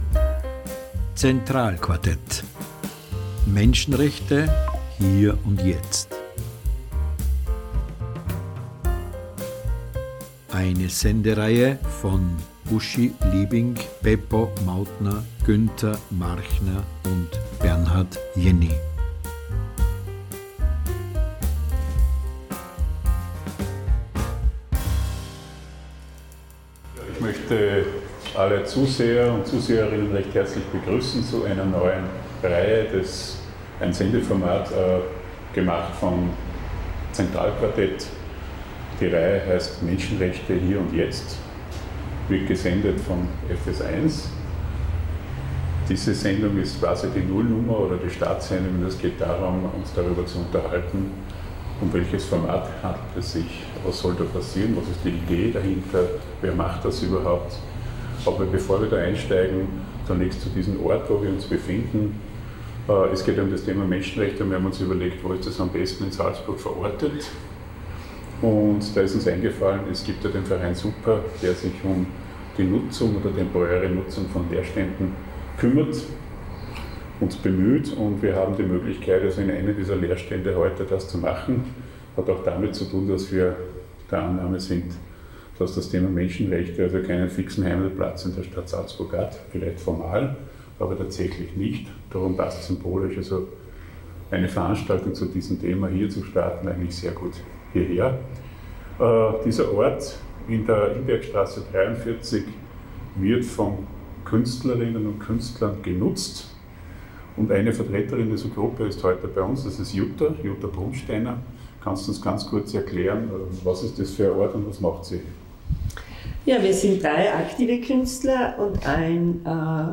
Diese Vol.0 wurde im Leerstand Imbergstrasse (Verein SUPR) gedreht.